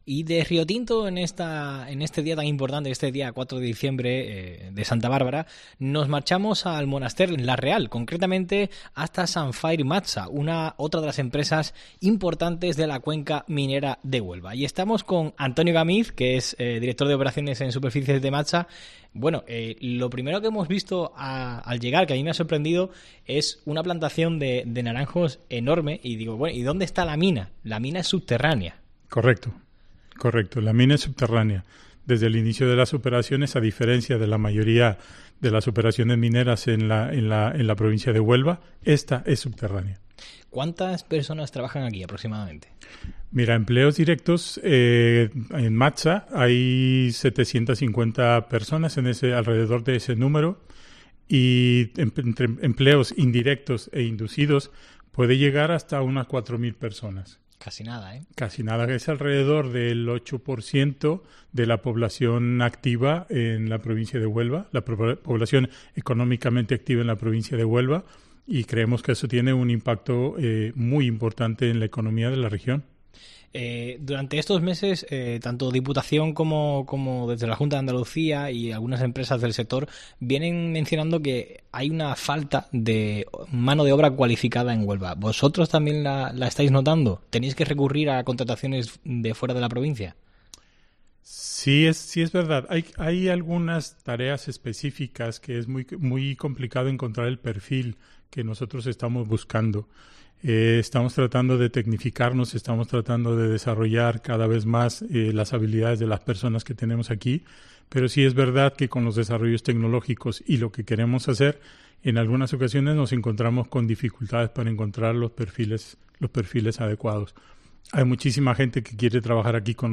Visitamos la mina de Aguas Teñidas de Sandfire MATSA durante el día de Santa Bárbara para conocer el estado del sector y a una de las principales...
Entrevista en Sandfire MATSA